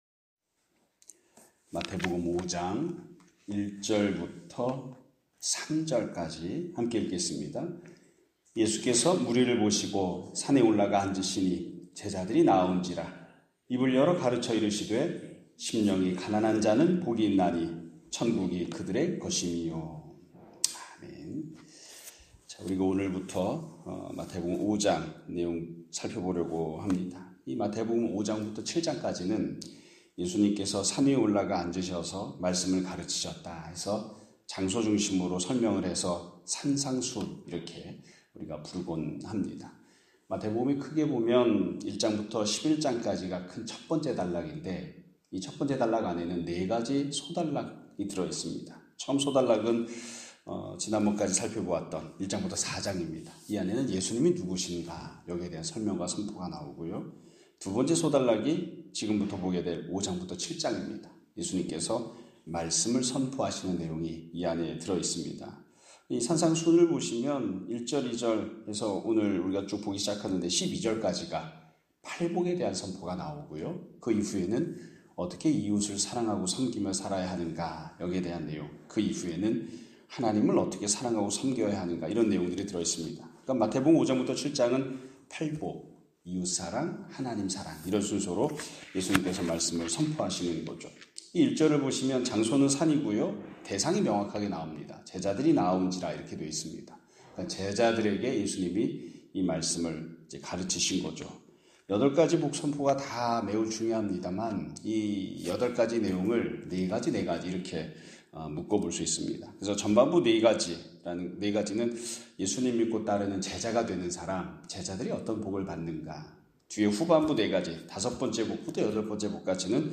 2025년 5월 7일(수 요일) <아침예배> 설교입니다.